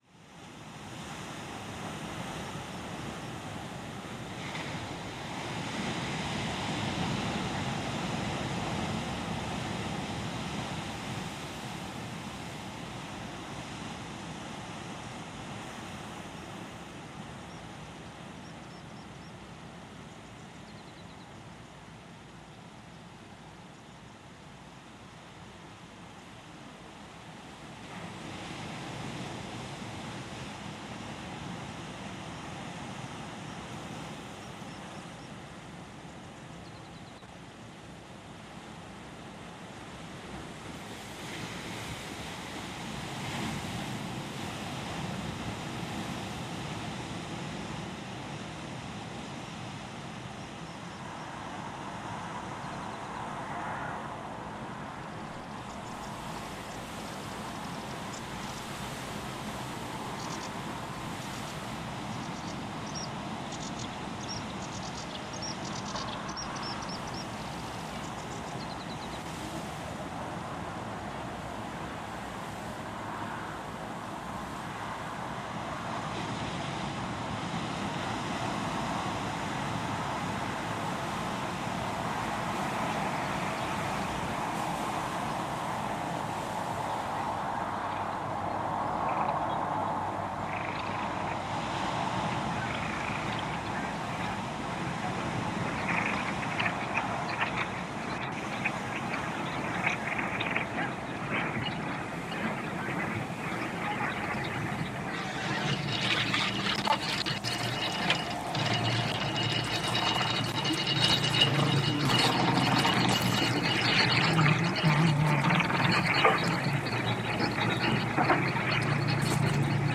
"The Conduction Series" is a collaborative live radio broadcast produced by sound and transmission artists across the Americas on Wave Farm’s WGXC 90.7-FM Radio for Open Ears in New York’s Upper Hudson Valley.